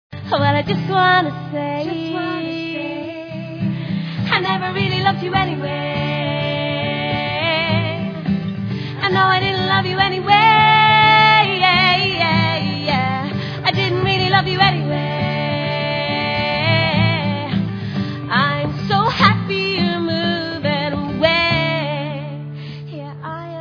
(Acoustic version)